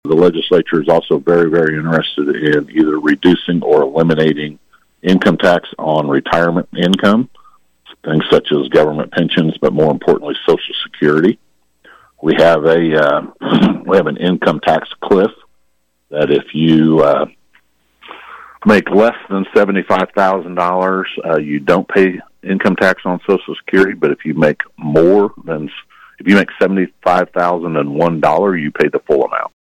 17th District Senator Jeff Longbine of Emporia was KVOE’s Newsmaker 2 guest Wednesday as KVOE continued its legislative previews.